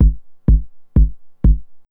HsDisco / Drum / KICK001_DISCO_125_X_SC3.wav